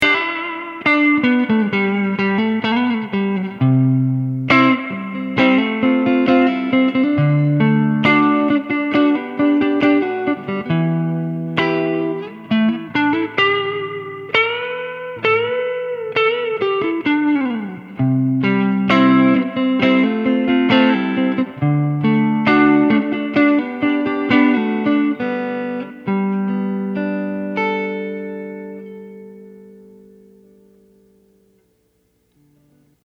I just recorded in an open room with no filtering. BTW, I used a Strat with a prototype Aracom RoxBox 18 Watt Amp with a Jensen 1 X10 speaker.
Spring